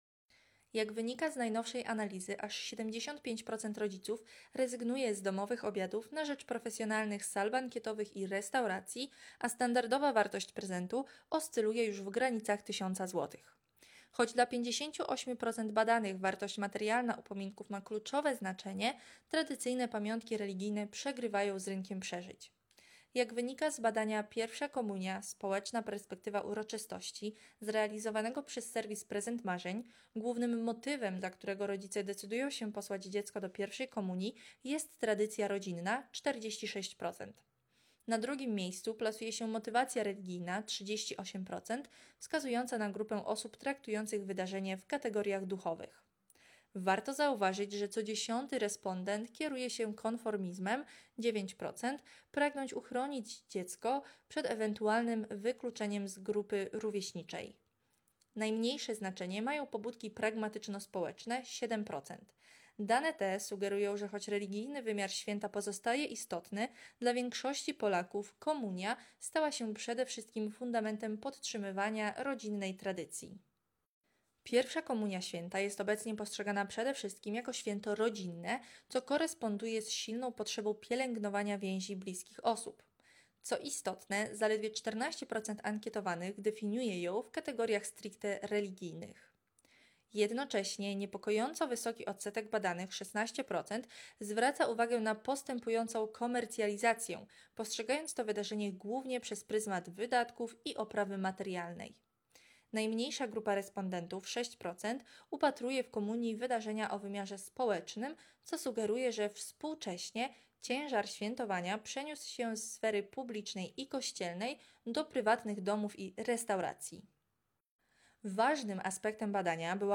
sonda_komunie.m4a